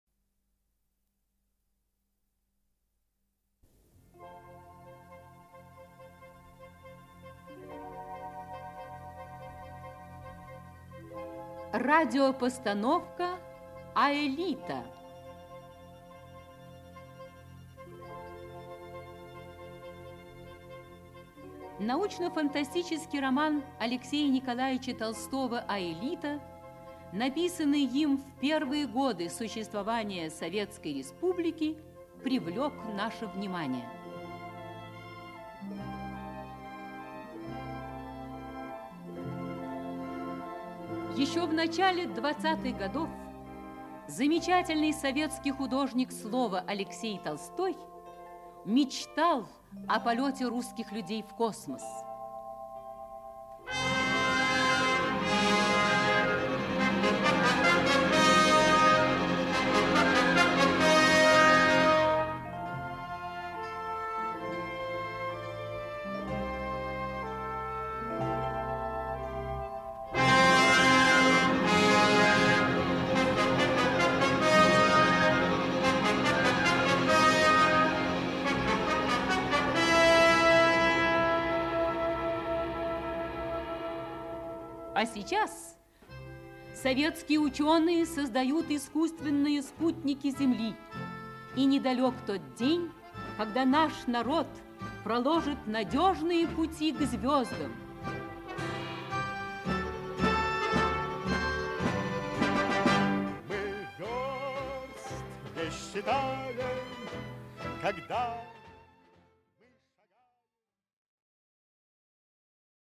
Аудиокнига Аэлита. Часть 1 | Библиотека аудиокниг
Часть 1 Автор Алексей Толстой Читает аудиокнигу Алексей Грибов.